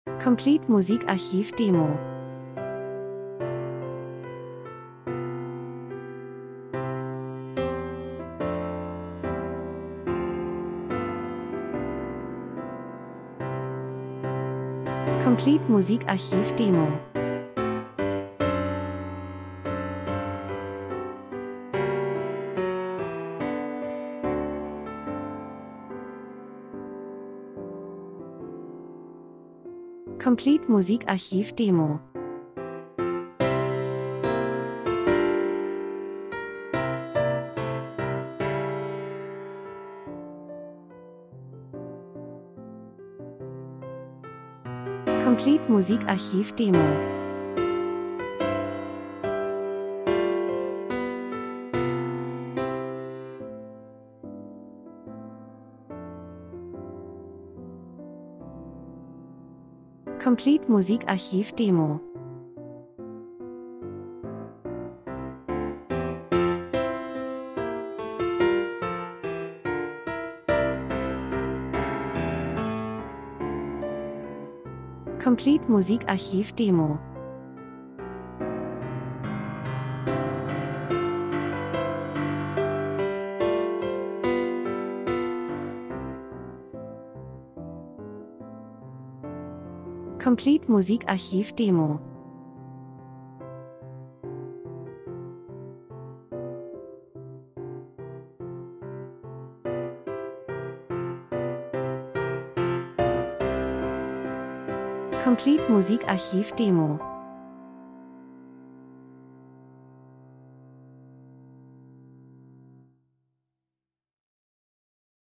schreitend optimistisch gut gelaunt hoffnungsvoll